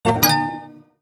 UI_SFX_Pack_61_30.wav